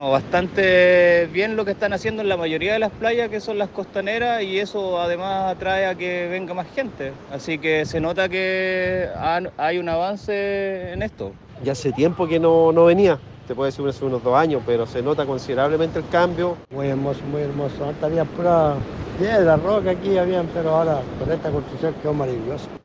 En ese sentido, visitantes del balneario valoraron los trabajos desarrollados en el lugar.